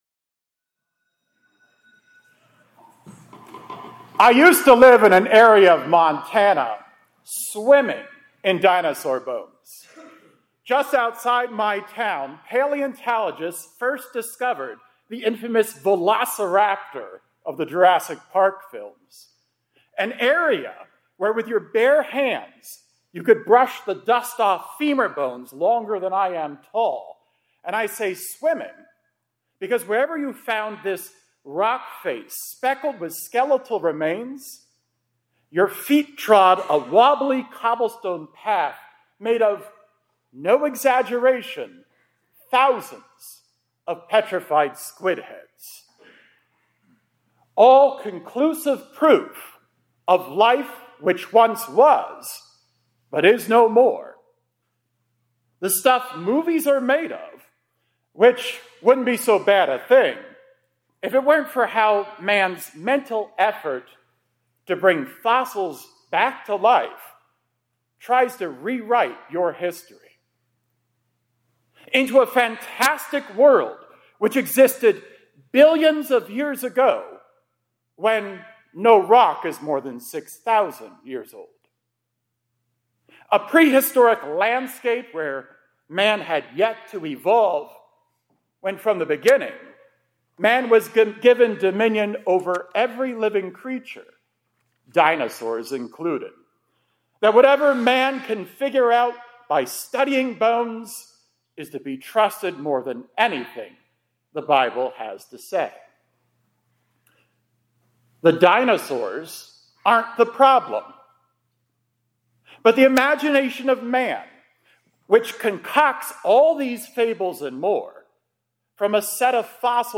2026-04-13 ILC Chapel — God Brings Fossils to Life